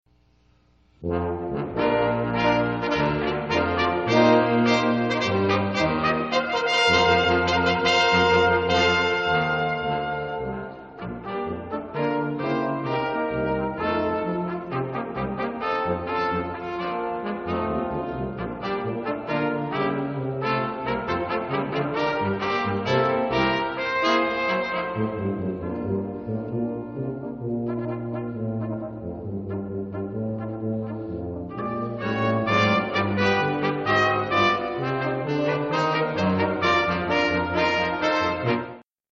St. Rose Concert Series 2006
Polished Brass
St. Rose Catholic Church